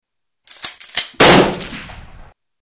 Shot gun sound